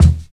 45 KICK 8.wav